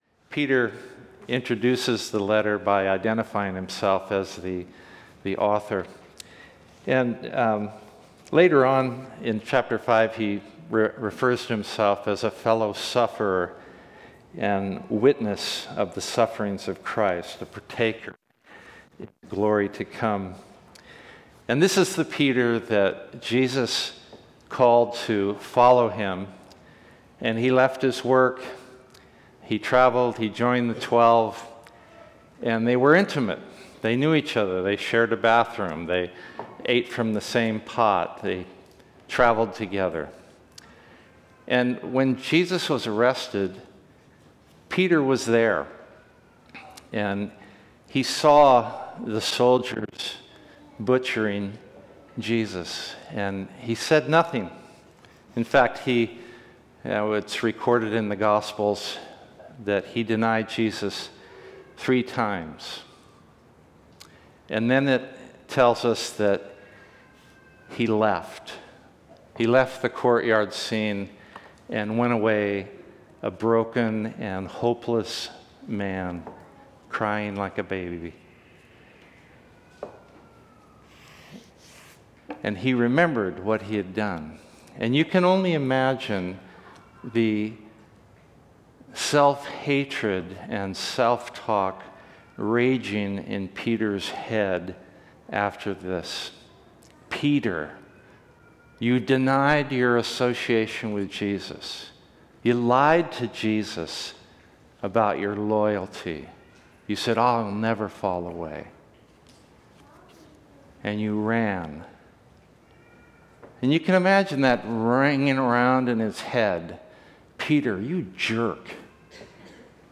Series: Guest Preacher
Service Type: Sunday Worship